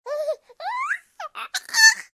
tickle3.ogg